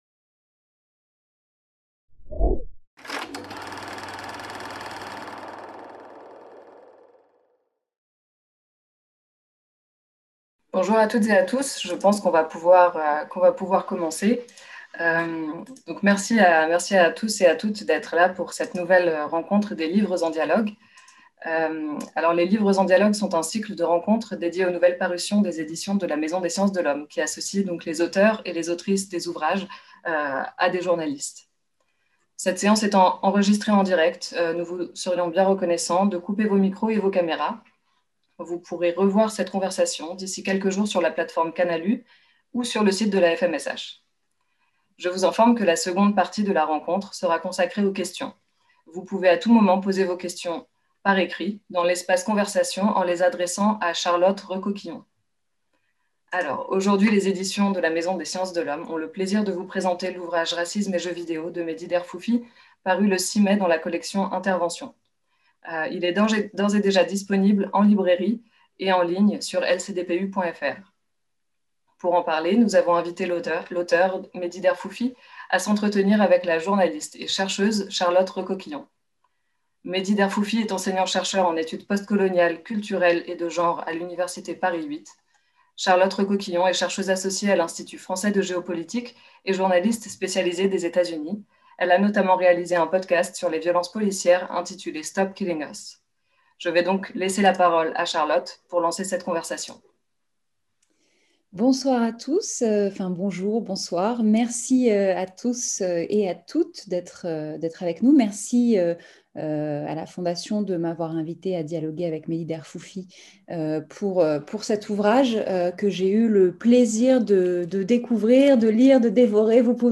Soirée de présentation de l'ouvrage "Racisme et jeux vidéo" | Canal U
À l'occasion de la parution de l'ouvrage Racisme et jeu vidéo (coll. Interventions), les Éditions de la Maison des sciences de l'homme organisent une soirée de présentation de l'ouvrage.